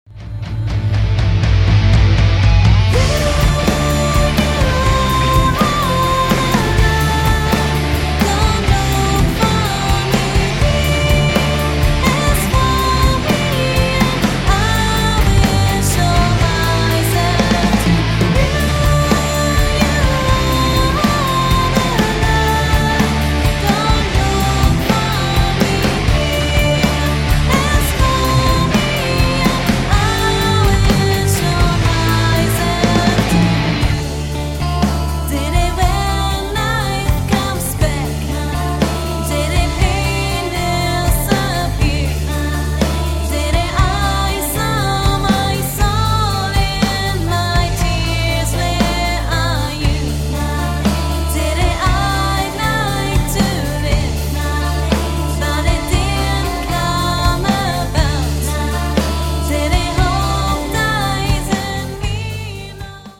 Guitars
Keyboard
Drums